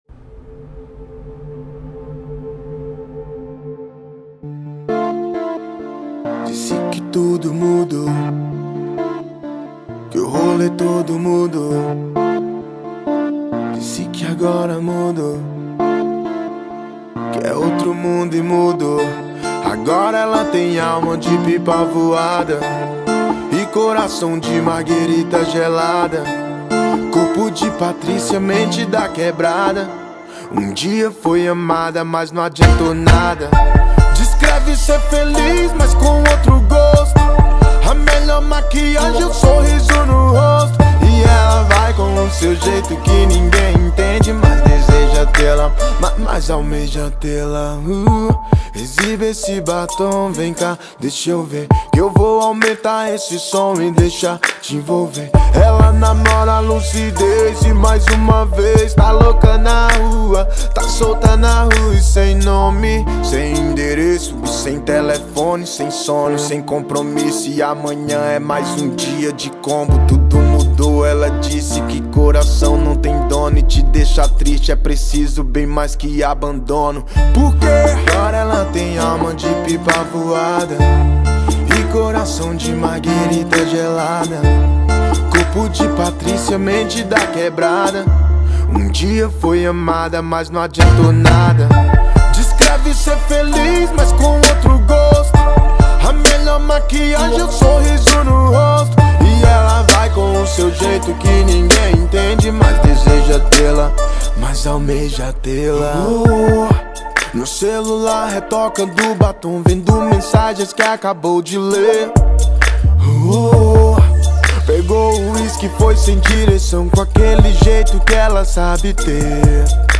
2025-03-17 19:32:36 Gênero: Rap Views